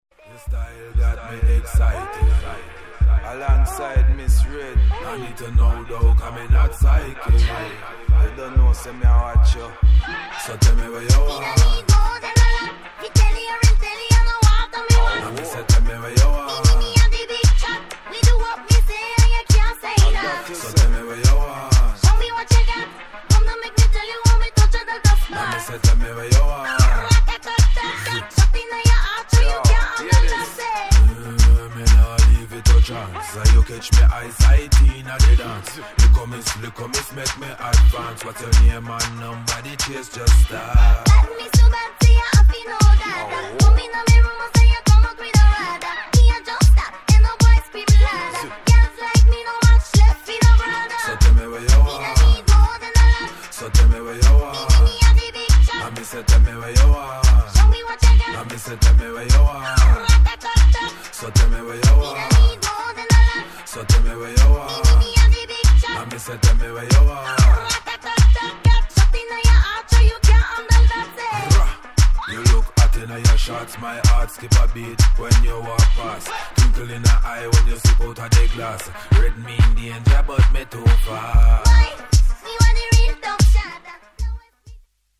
[ BASS / DUB / REGGAE ]